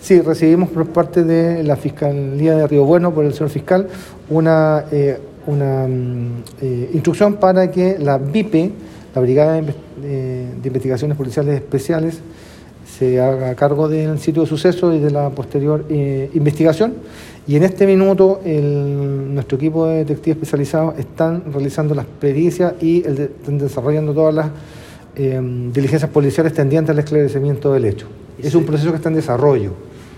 Prefecto Inspector Alex Schwarzenberg Ramírez, jefe regional de la PDI